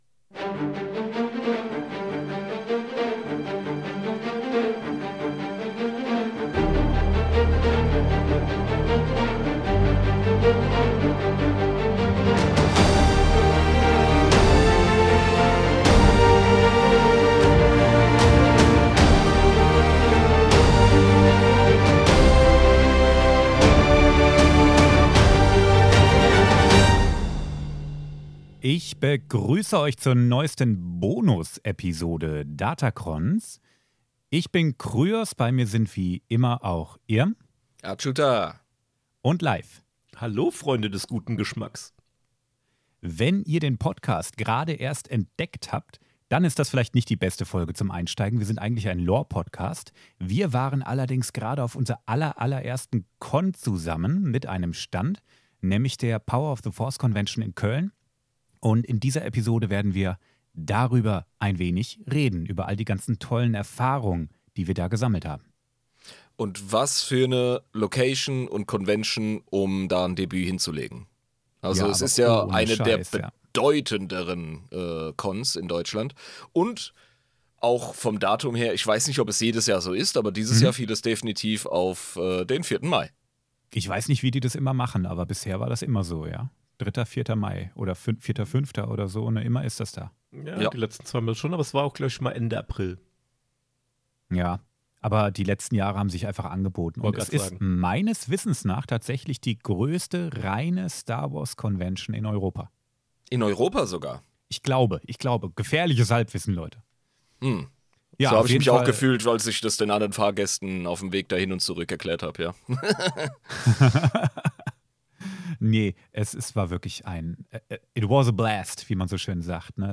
in lockerer Atmosphäre